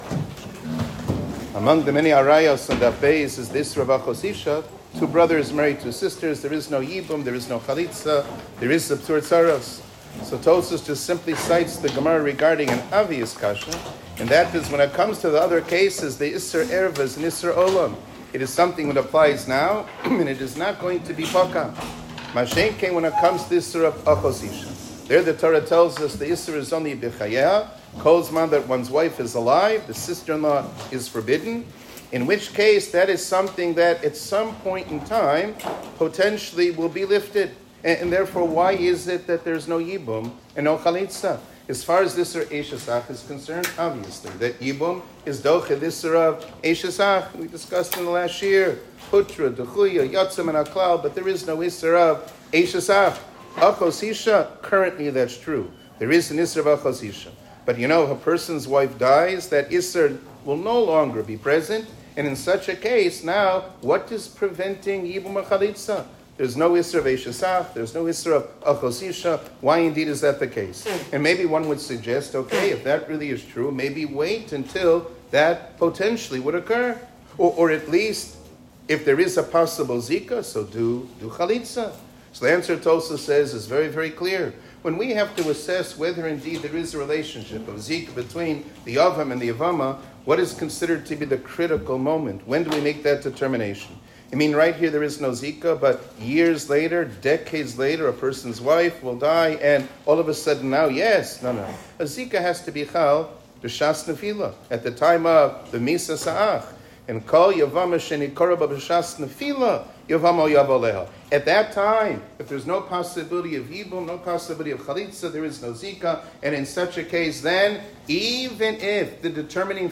שיעור כללי - גילוי עריות